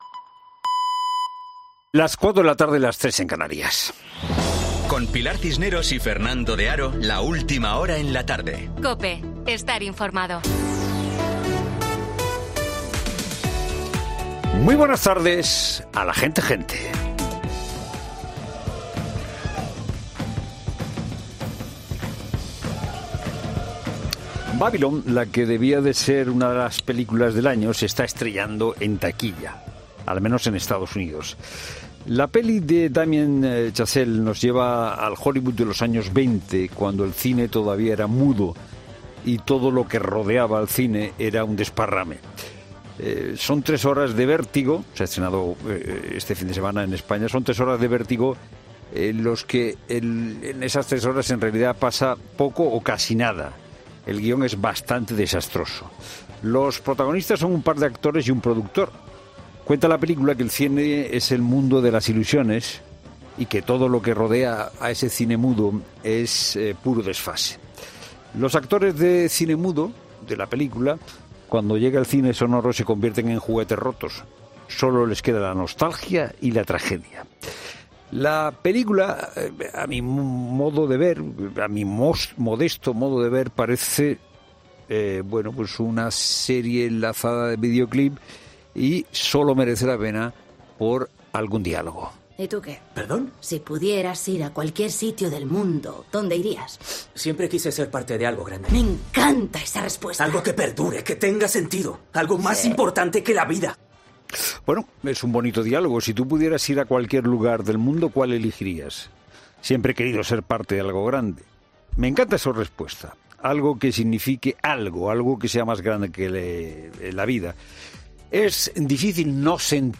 Boletín de noticias de COPE del 23 de enero de 2023 a las 16.00 horas